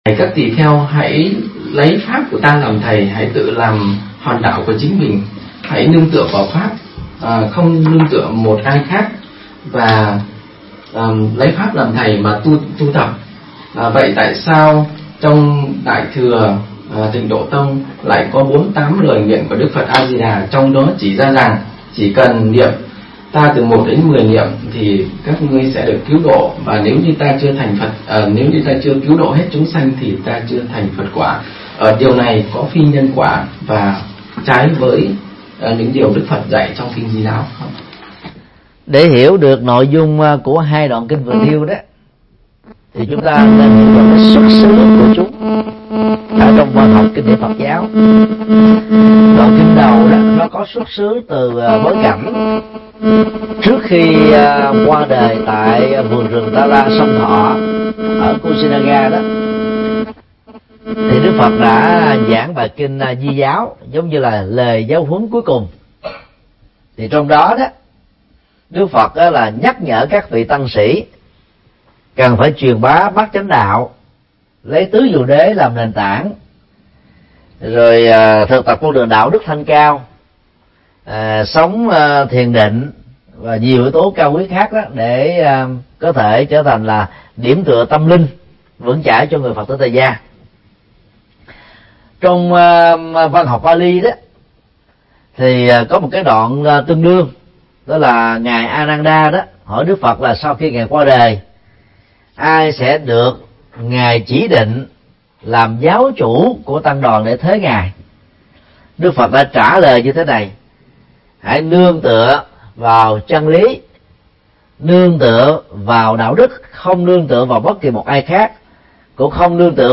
Vấn đáp: Tự lực và Tha lực – Thượng Tọa Thích Nhật Từ mp3